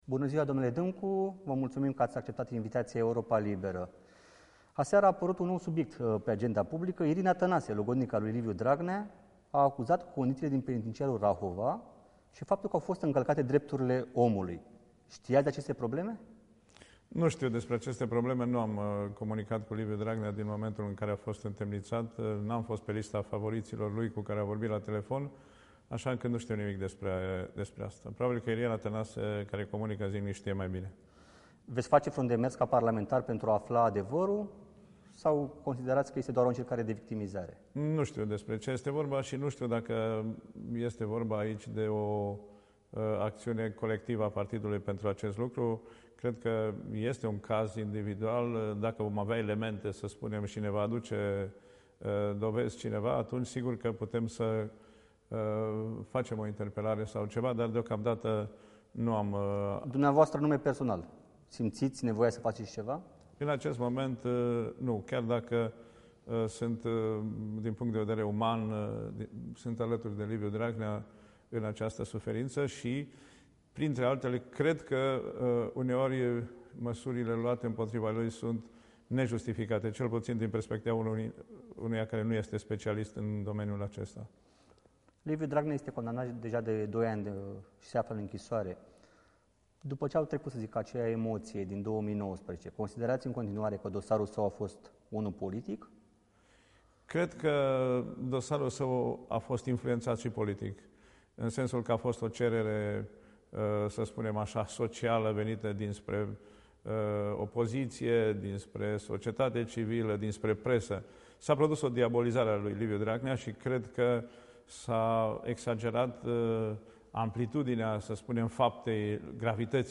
Interviu despre politică și fotbal | Vasile Dîncu: Intrarea PSD în Opoziție a fost un dar dumnezeiesc, nu exclud o guvernare cu PNL